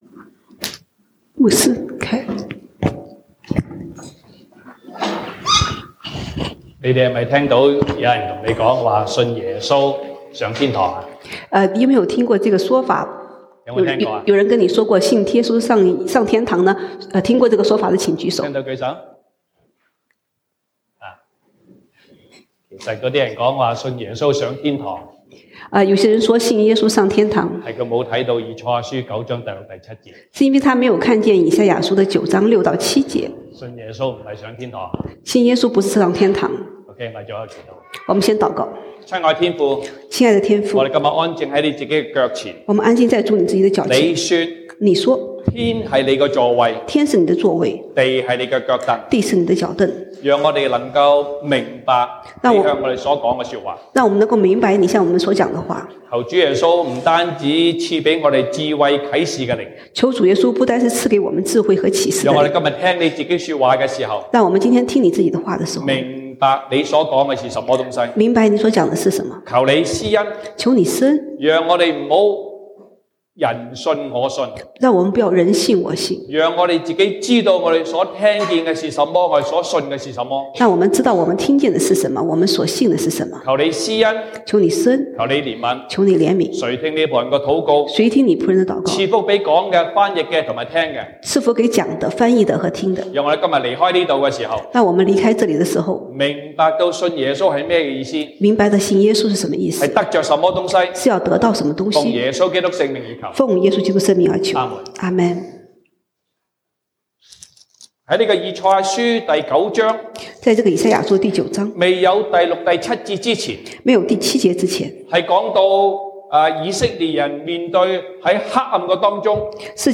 聖誕節聚會(粵語/國語) Christmas Service: 以賽亞書 Isaiah 9:6-7
Service Type: 聖誕節聚會 Christmas Service